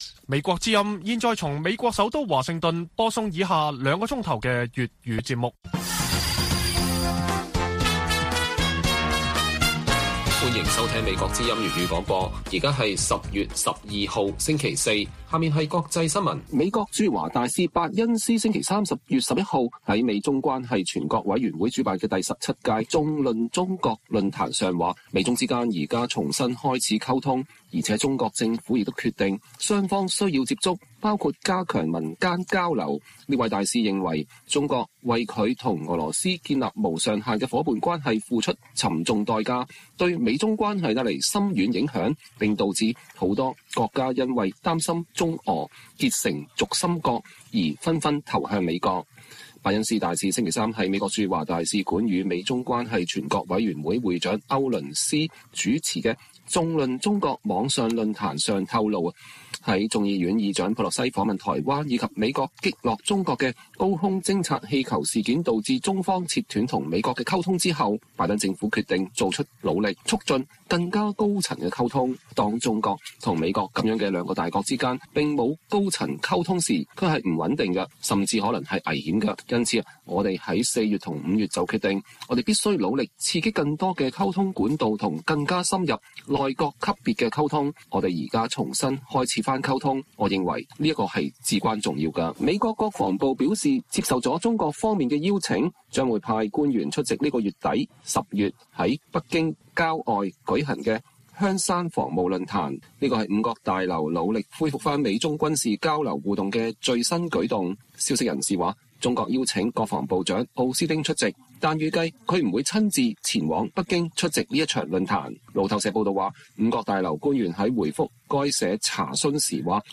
粵語新聞 晚上9-10點: 伯恩斯大使：美中恢復高層接觸至關重要，中國為支持俄羅斯付出沉重代價